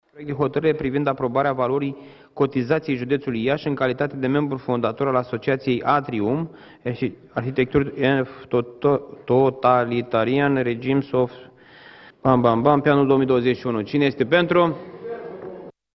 Limba engleză îi dă bătăi de cap președintelui Consiliului Județean Iași, Costel Alexe. Acesta a încercat, într-o ședință, să pronunțe denumirea în engleză a unei asociații, dar nu a reușit, așa că a renunțat, pur și simplu.